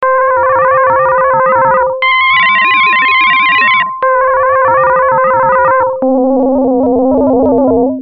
6.コンピュータぽい音色